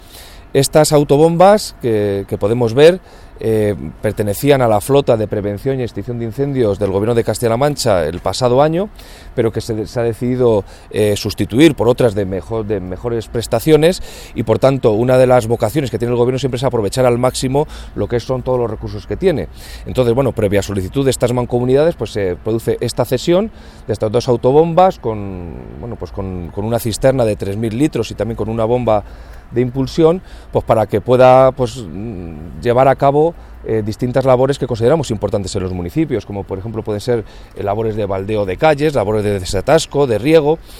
El delegado de la Junta en Guadalajara, Alberto Rojo, habla de la cesión de dos autobombas por parte del Gobierno regional a las mancomunidades de La Sierra y Campo-Mesa.